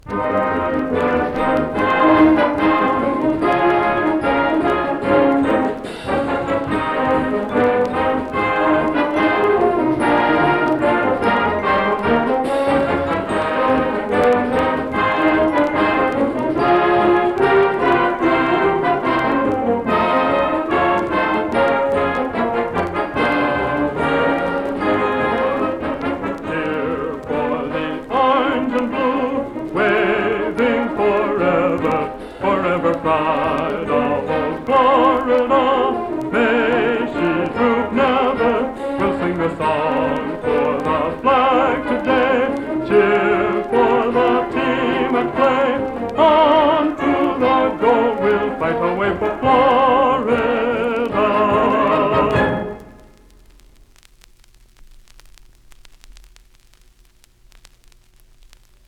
band and vocal